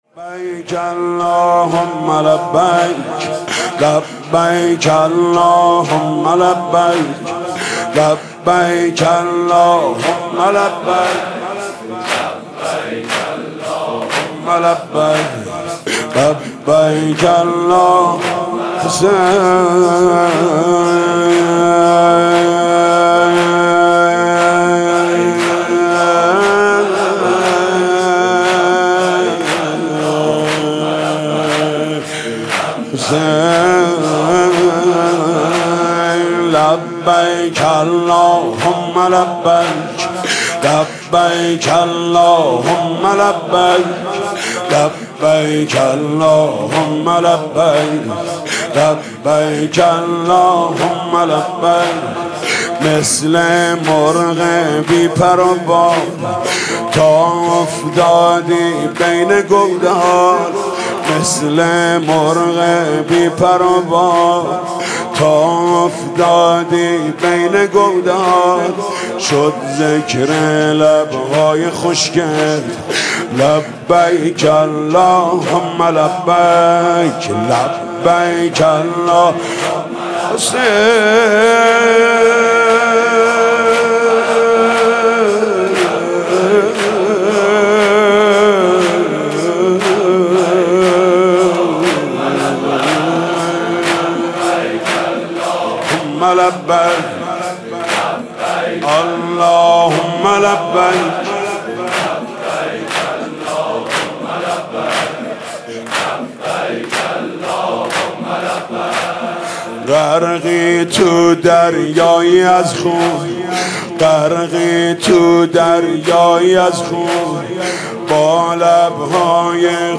شب سوم رمضان 95، حاح محمدرضا طاهری
واحد، زمینه